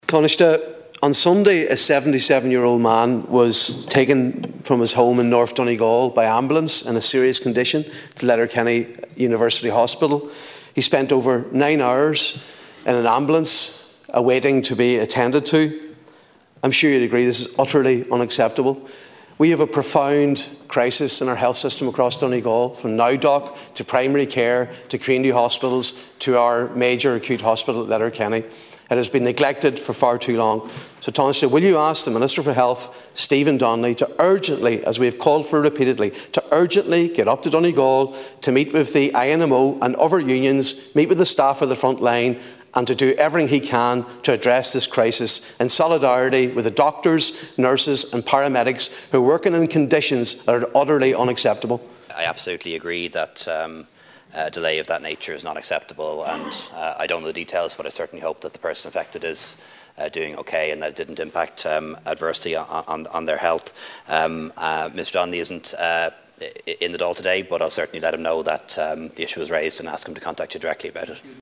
The issue was raised in the Dail this afternoon by Donegal Deputy Padraig MacLochlainn.
Deputy MacLochlainn called for the Government to step in and bring an end to the current crisis within Donegal’s health service: